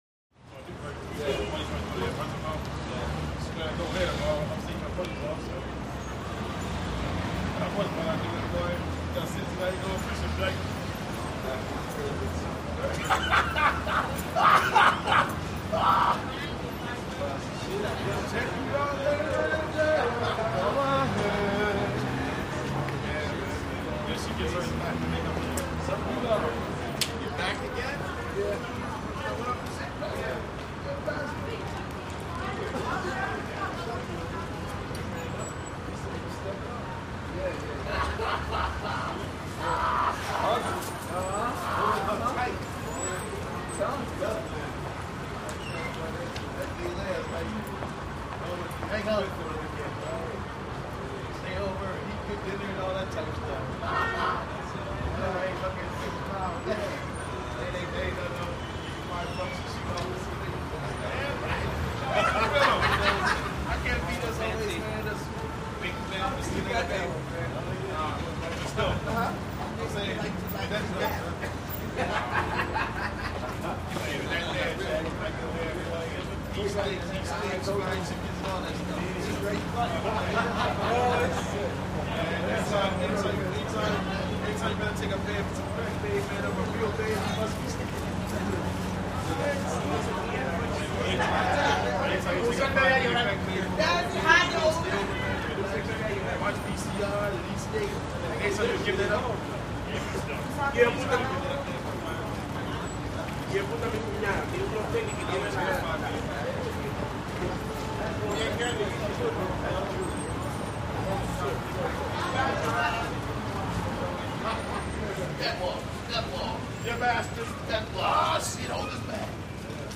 City Sidewalk Walla, Medium Perspective Group Of Black Males Hanging Out, Laughing. Traffic Ambience Medium Close To Distant With Busses By Pedestrian Footsteps. Boy Cries At Tail.